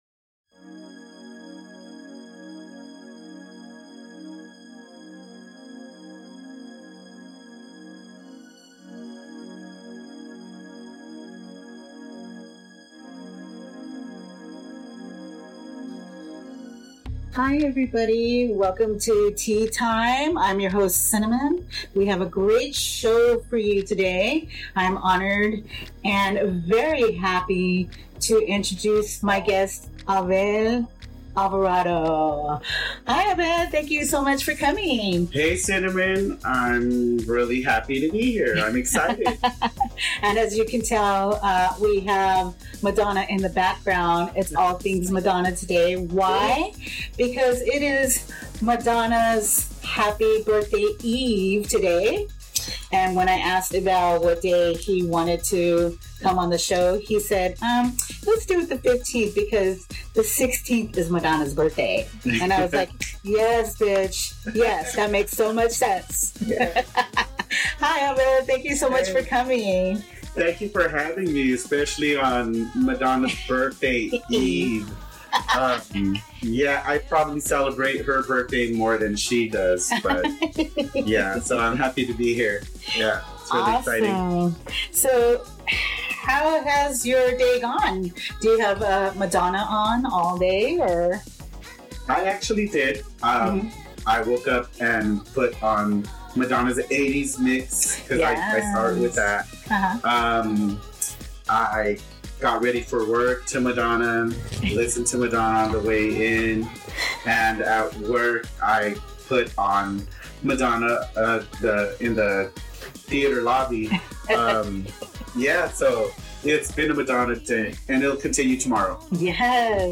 This episode of Tea Time aired live on CityHeART Radio on Tuesday Aug. 15 at 7pm.
Produced by CityHeART’s Art from Ashes Production Note: Please accept our sincerest apologies for the delay on this episode and for the poor audio quality.